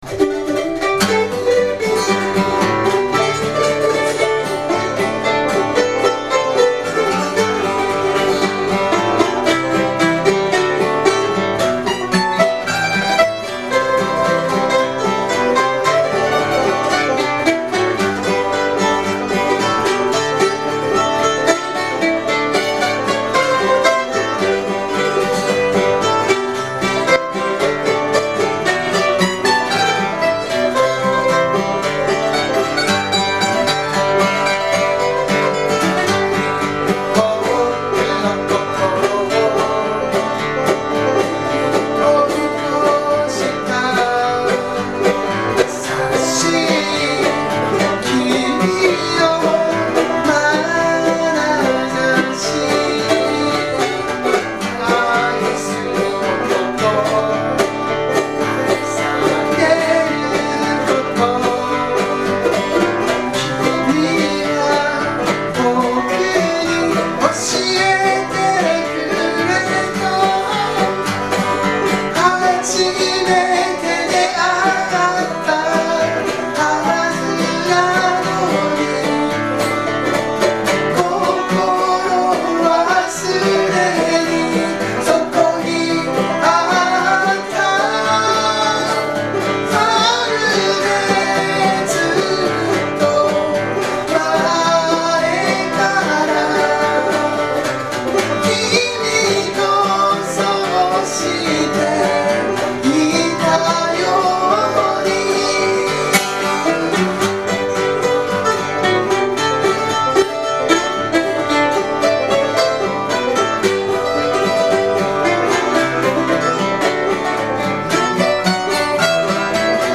Bluegrass style Folk group
Key of G
この曲も最初はフォーク調だったのですが、ブルーグラススタイルにアレンジしてみました。
企画名: Studio Live III
録音場所: 与野本町BIG ECHO
コーラス、マンドリン
バンジョー
ボーカル、ギター